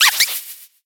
Cri de Chlorobule dans Pokémon X et Y.